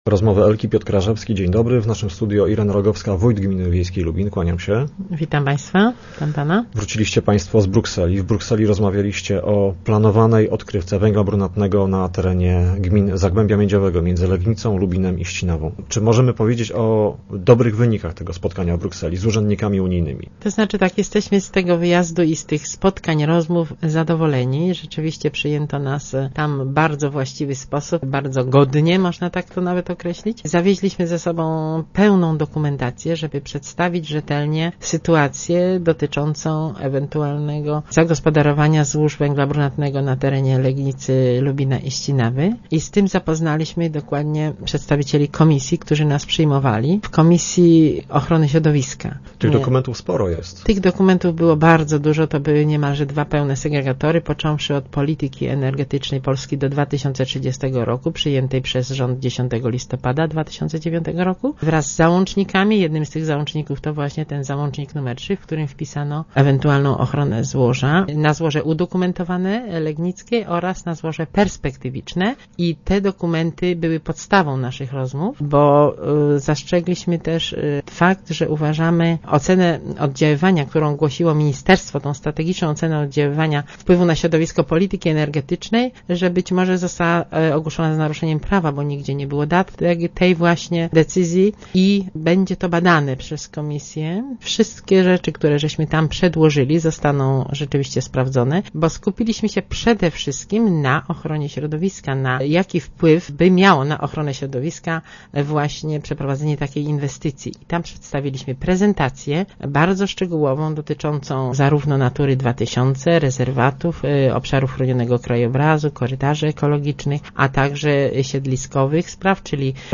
rogowska_irena.jpgWójtowie gmin położonych na węglu brunatnym, wrócili z Brukseli, gdzie przekonywali unijnych urzędników, że polski rząd swoimi przygotowaniami do uruchomienia odkrywki mógł złamać wspólnotowe prawo w zakresie ochrony środowiska. O szczegółach rozmawialiśmy z Ireną Rogowską wójt gminy Lubin.